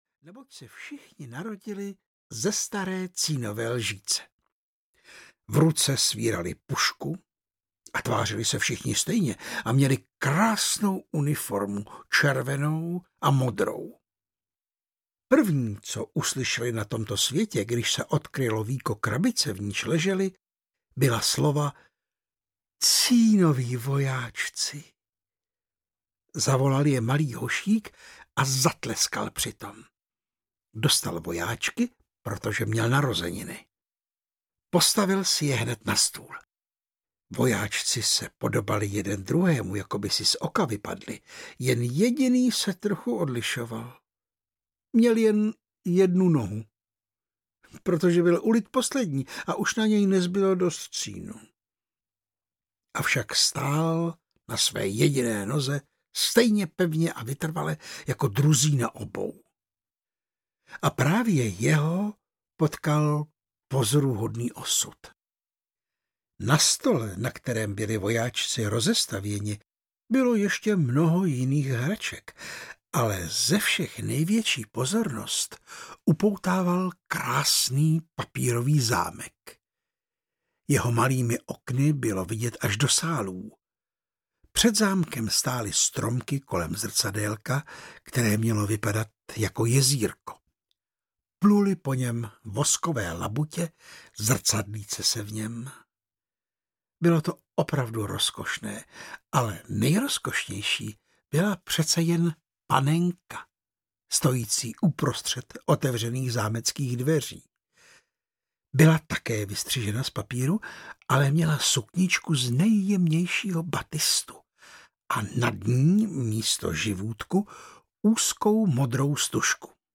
Ukázka z knihy
• InterpretVáclav Knop